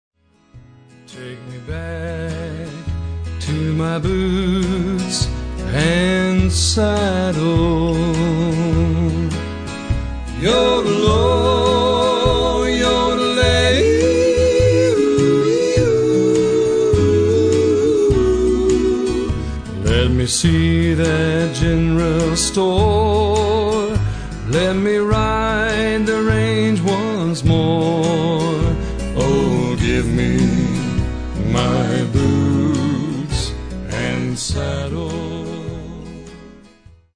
Recorded in Nashville in 2000